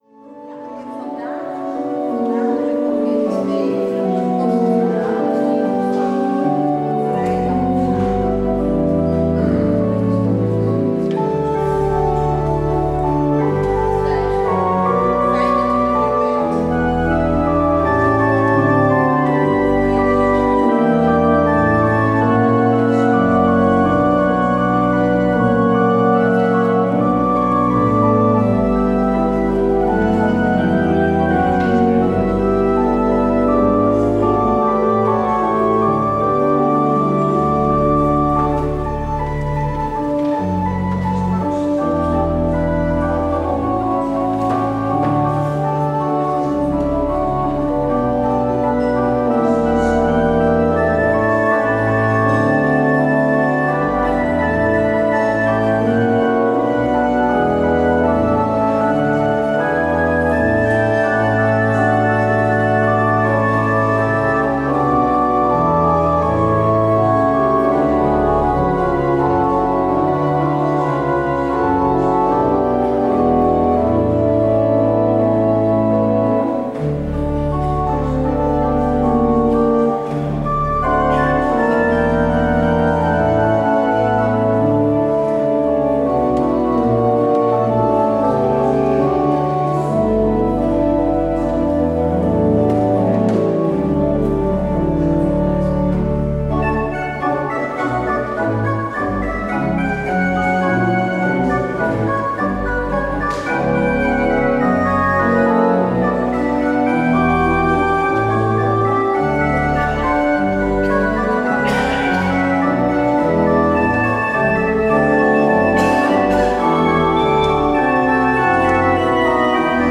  Beluister deze kerkdienst hier: Alle-Dag-Kerk 28 mei 2025 Alle-Dag-Kerk https